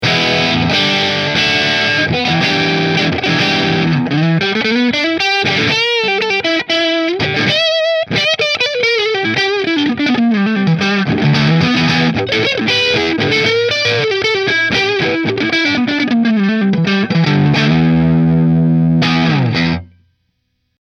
• Mahogany Hollow Body with AAA Quilt Maple Top
• 2 Seymour Duncan SH-1 ’59 Pickups
Prestige Heritage Hollow SB QM Middle Through Marshall